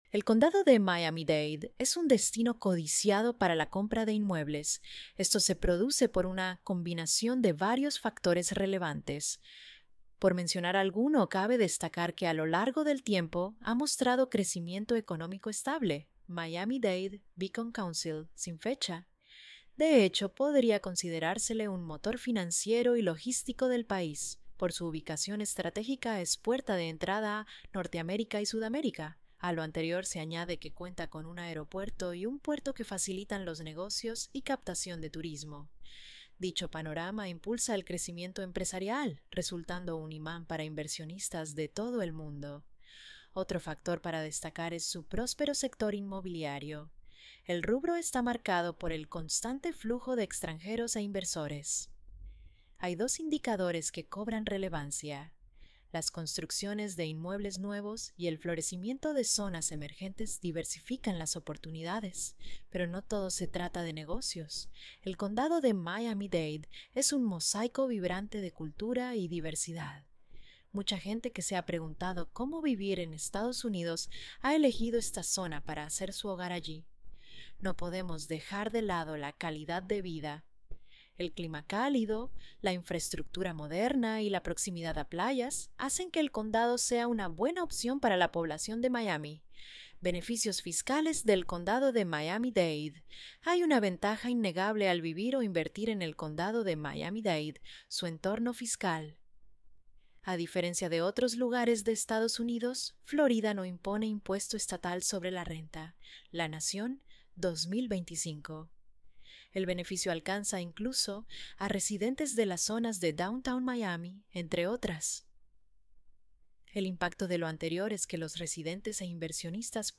▶ Escucha el artículo aquí: Condado de Miami Dade